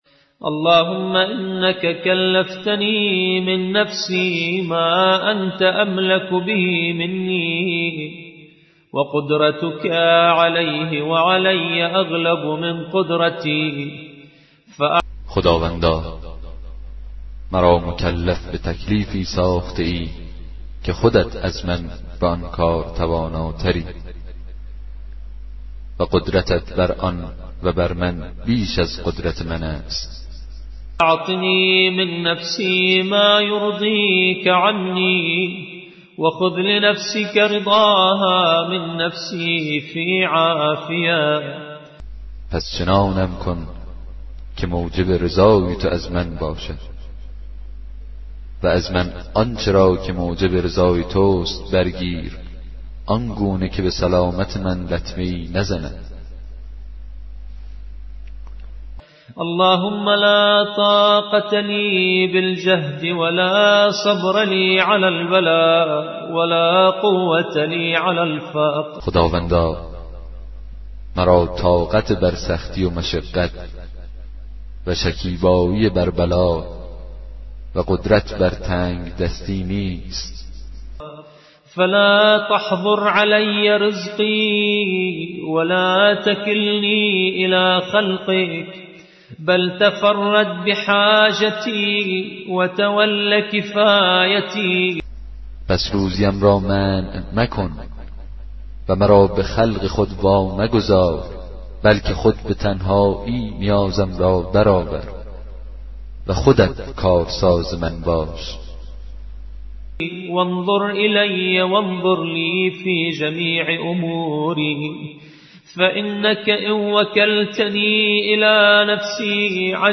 کتاب صوتی